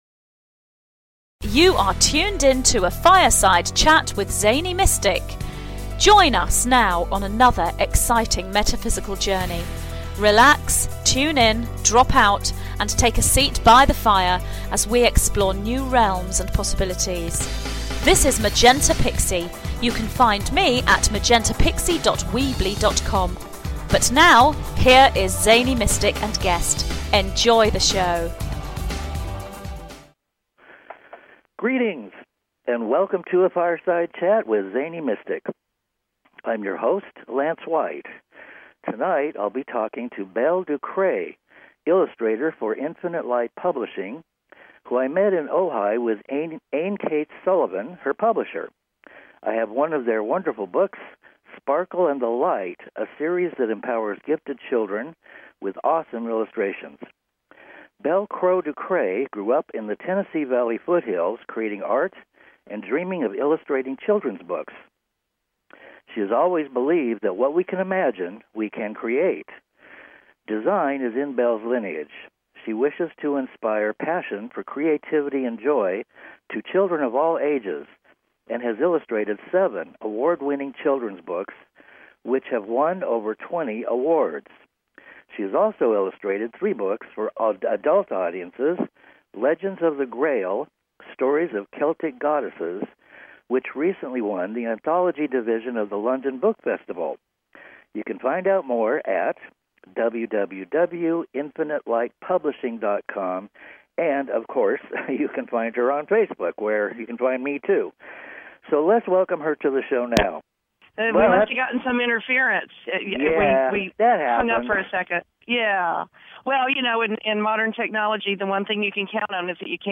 (*Note: due to technical difficulties, there were a couple of times the line dropped briefly)